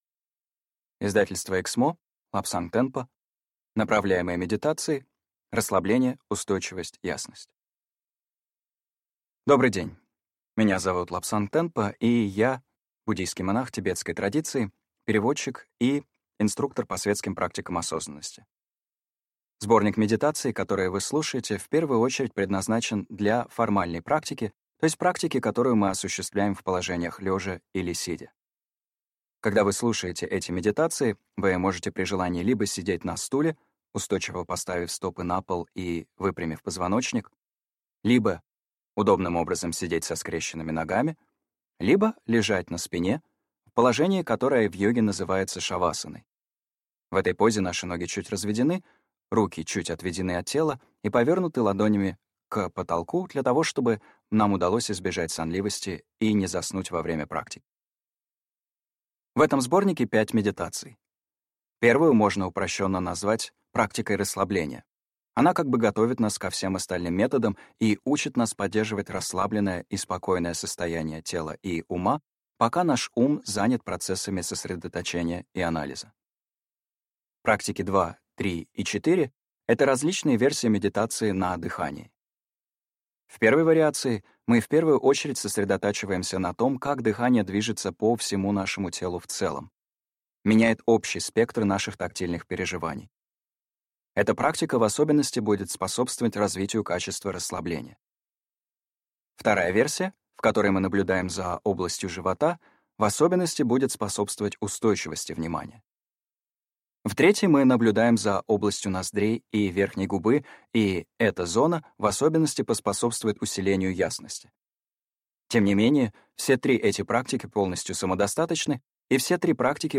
Аудиокнига Пять направляемых медитаций | Библиотека аудиокниг
Прослушать и бесплатно скачать фрагмент аудиокниги